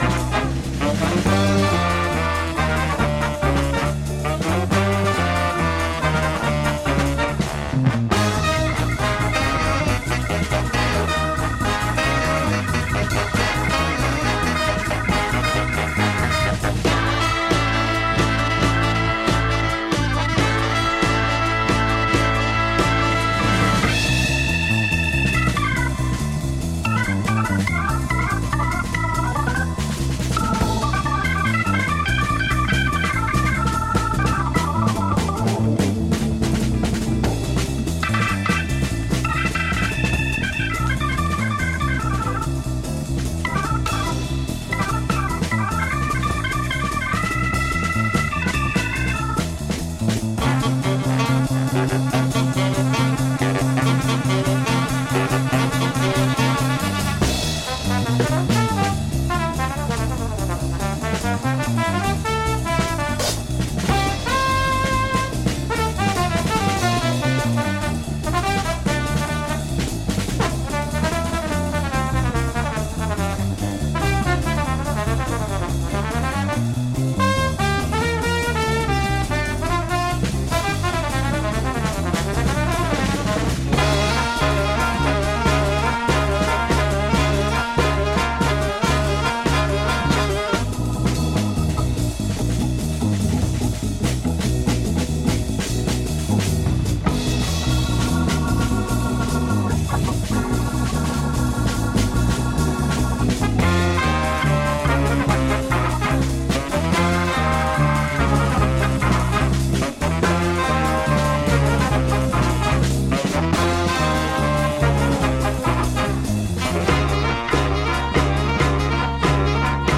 here accompanied by his orchestra